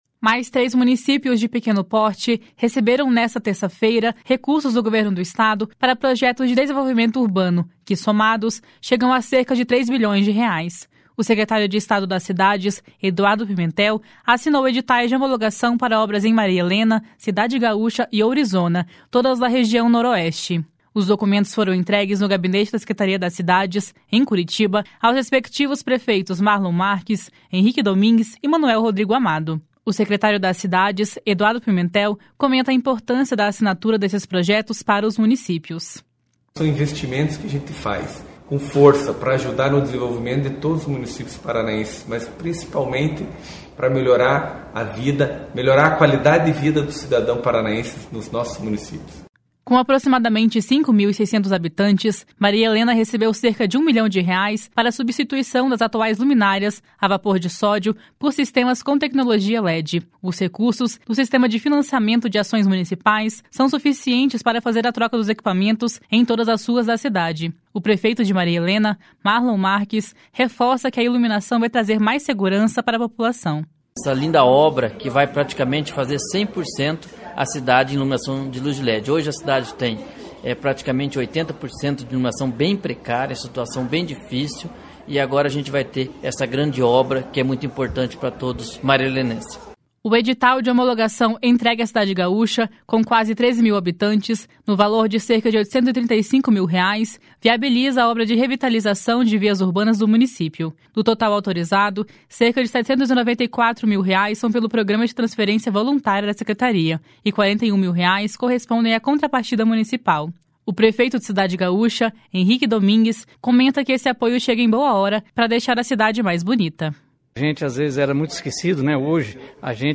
O secretário das Cidades, Eduardo Pimentel, comenta a importância da assinatura desses projetos para os municípios.
O prefeito de Maria Helena, Marlon Marques, reforça que a iluminação vai trazer mais segurança para a população.
O prefeito de Cidade Gaúcha, Henrique Domingues, comenta que esse apoio chega em boa hora para deixar a cidade mais bonita.